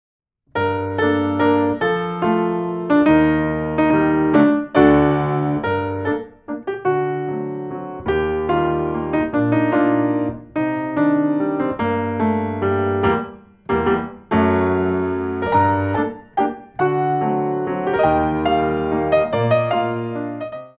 4 bar intro 3/4
64 bars